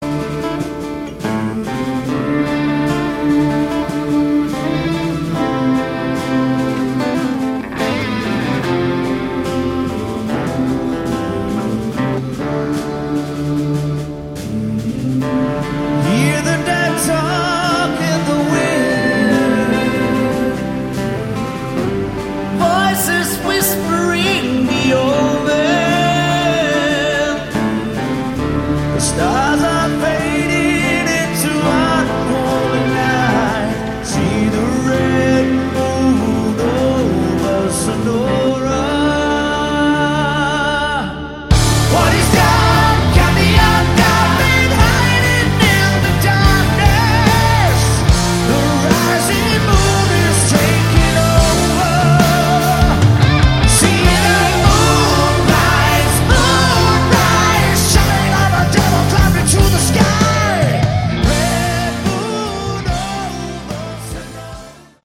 Category: Melodic Metal
vocals
guitar
bass
drums
keyboards